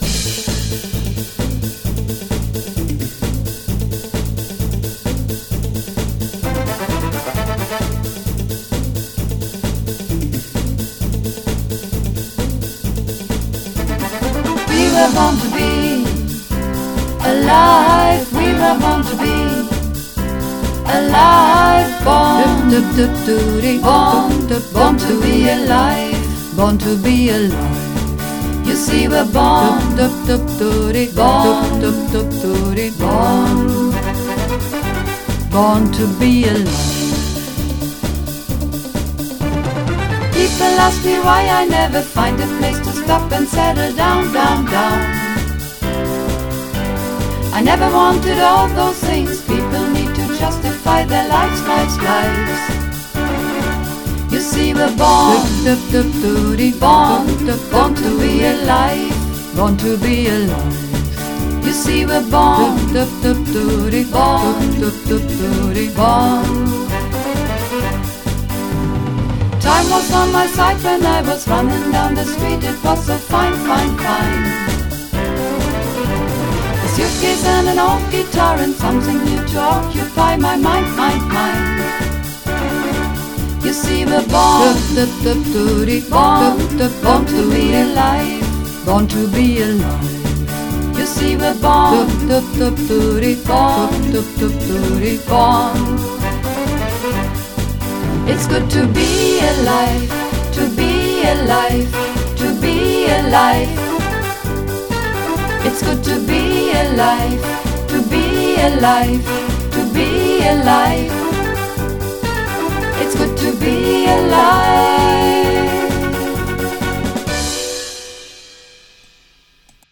Mehrstimmig